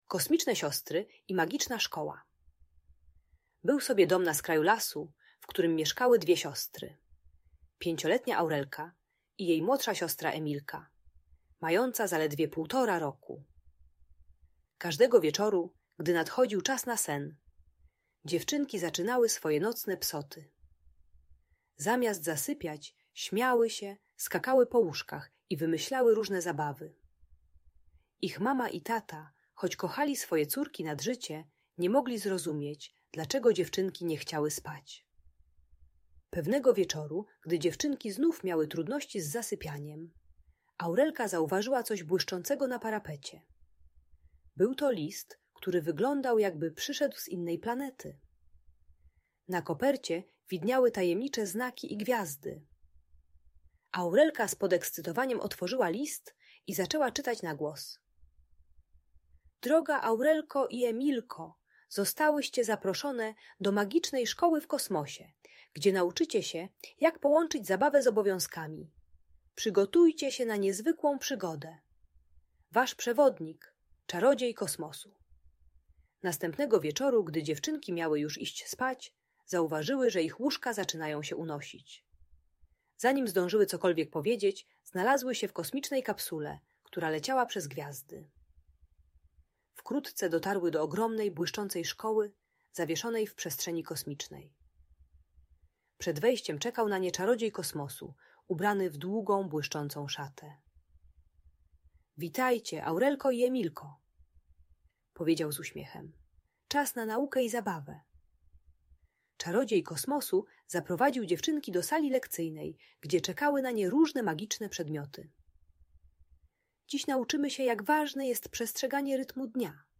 Audiobajka o usypianiu dla przedszkolaków.